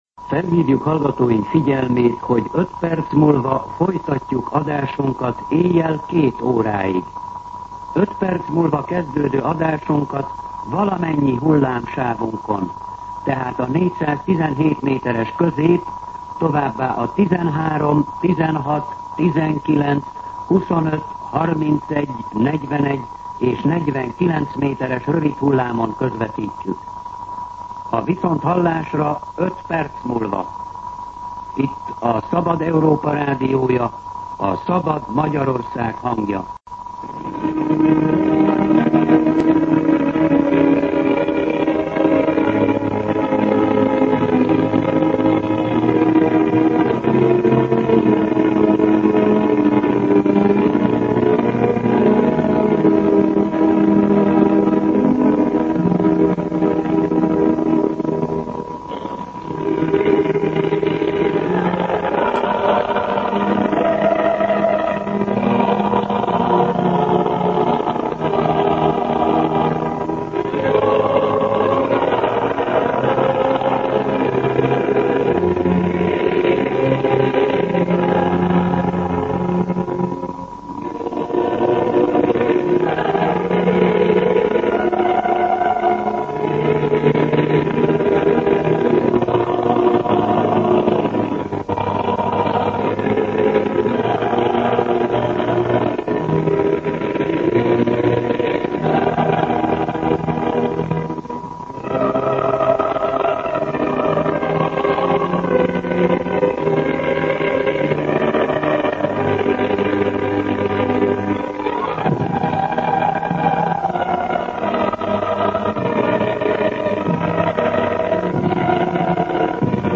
Himnusz